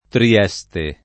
[ tri- $S te ]